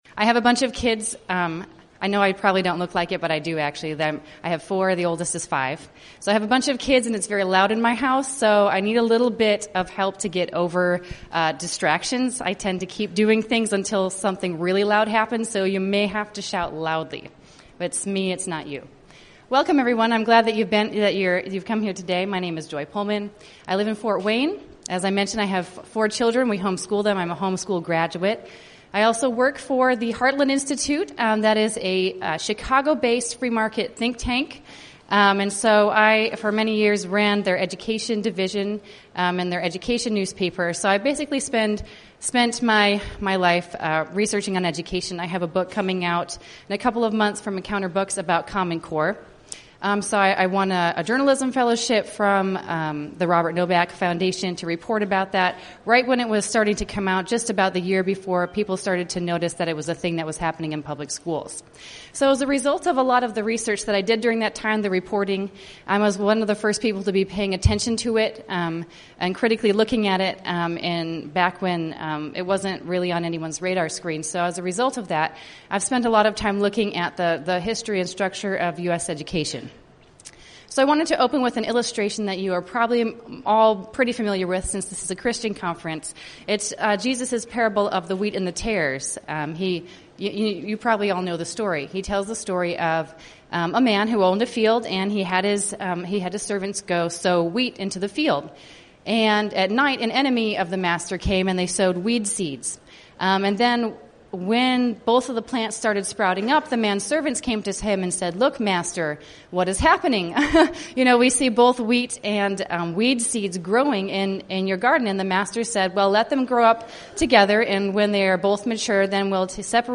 Recorded live at the IAHE Home Educators' Convention